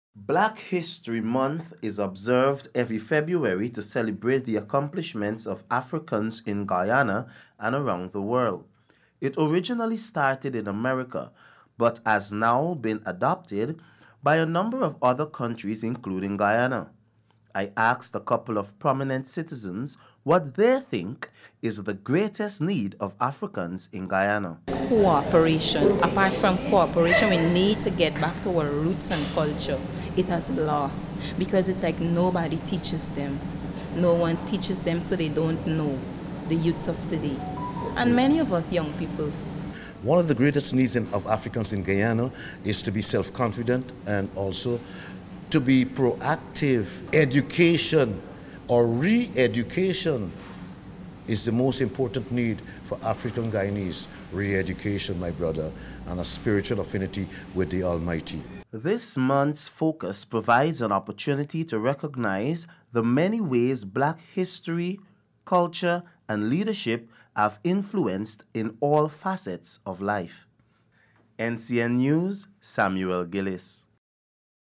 In a recent conversation with prominent Afro-Guyanese figures, NCN News delved into the pressing needs of Africans in Guyana and their aspirations for the future.